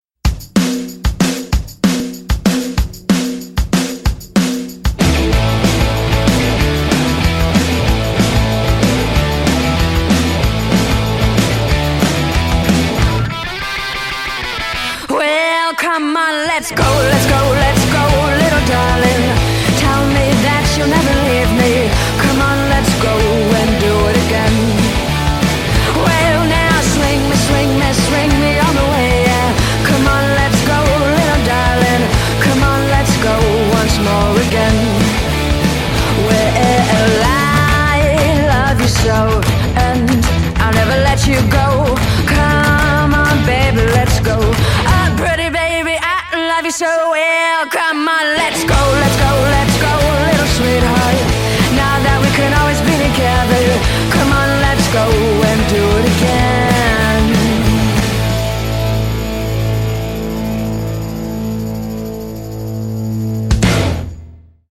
this is a fun mashup from the trio from San Antonio.